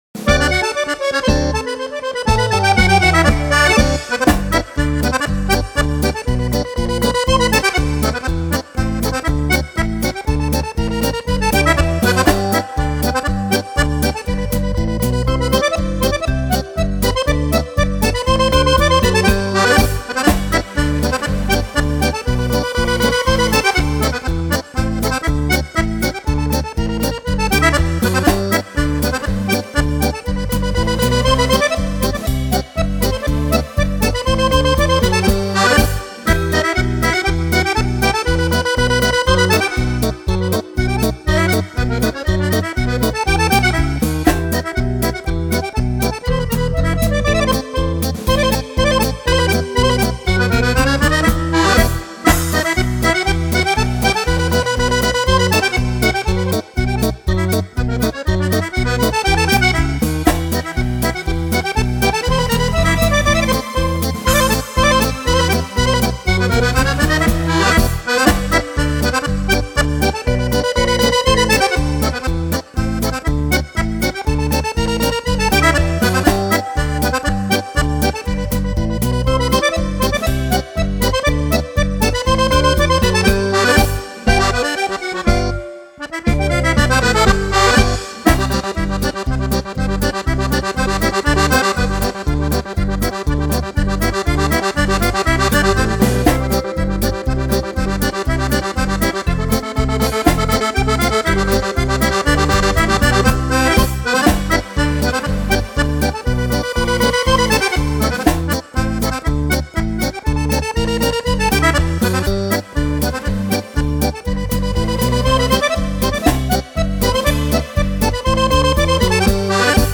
Polca
Polca per Fisarmonica